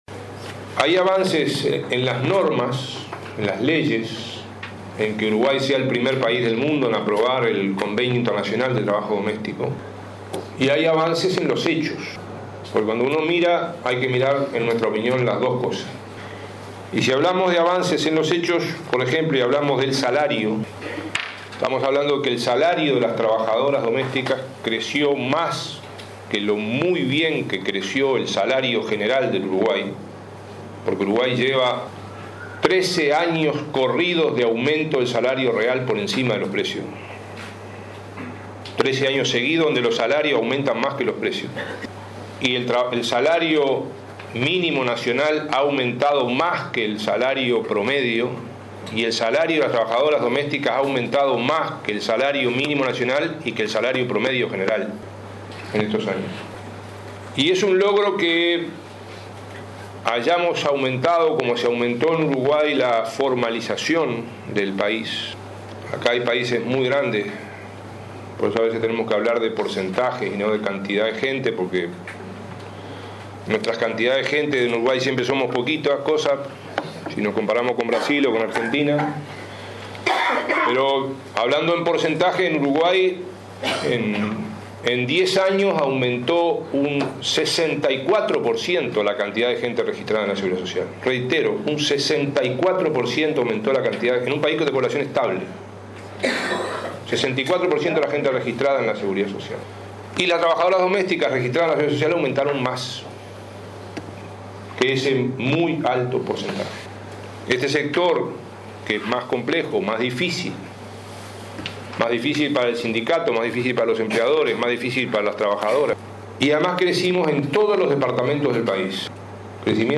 El ministro de Trabajo, Ernesto Murro, recordó que Uruguay es el primer país del mundo en aprobar el convenio internacional de trabajo doméstico. Dijo que el salario de este sector creció más que el salario general y que las registradas en seguridad social aumentaron en más de 64 % en 10 años. Murro participó del encuentro por los derechos de las trabajadoras domésticas del Mercosur, que se realiza este 8 y 9 en Montevideo.